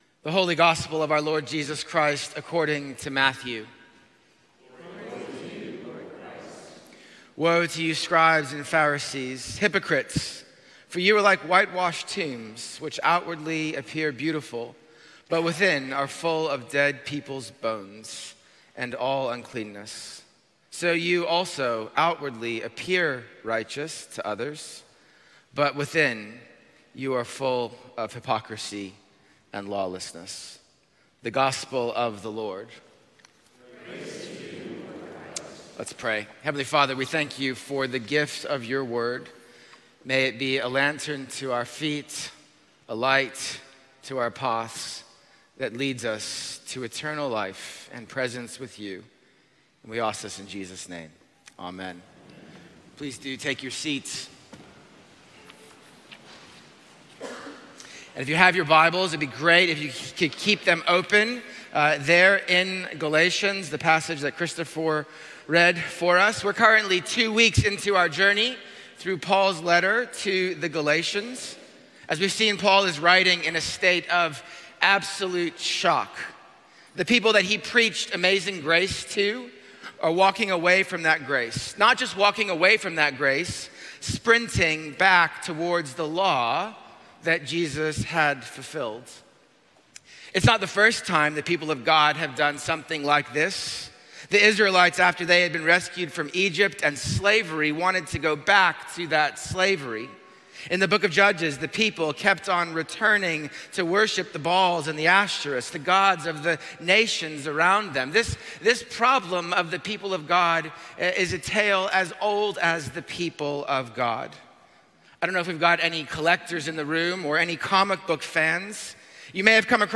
Sermon-March-1-2026.mp3